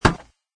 woodmetal.mp3